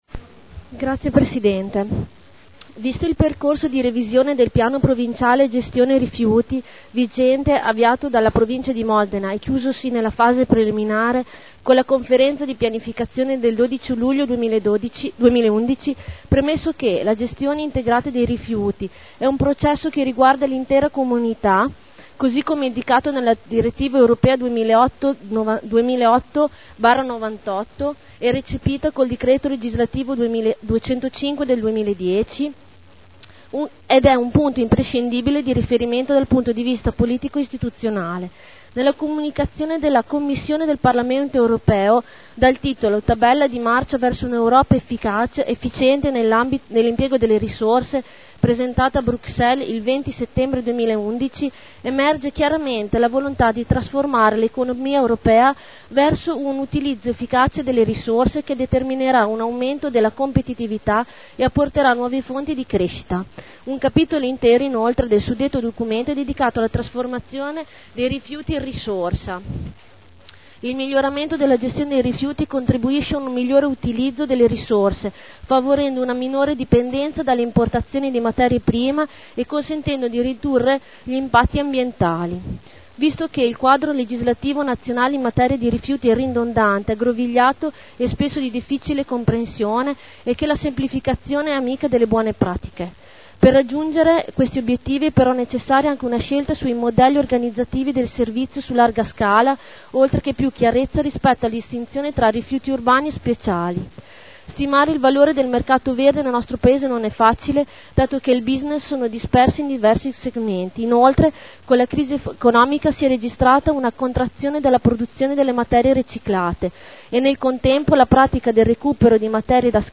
Claudia Codeluppi — Sito Audio Consiglio Comunale